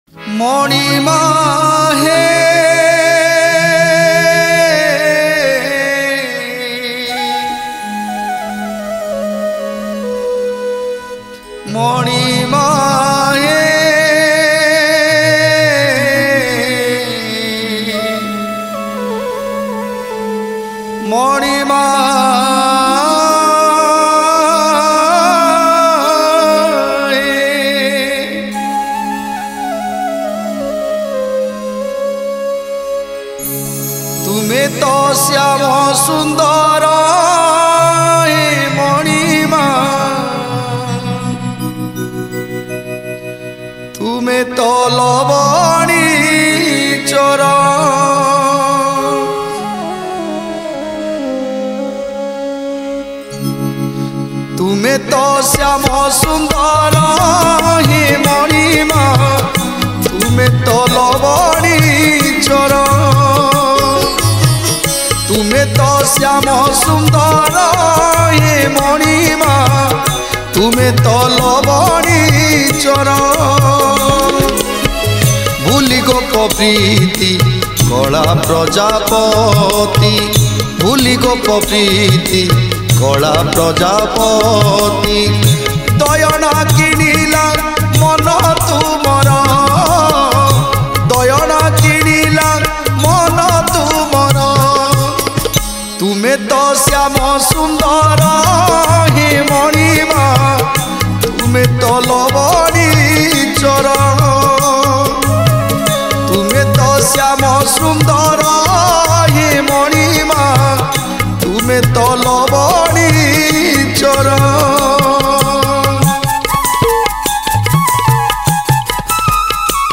Sri Sri Jagannath Stuti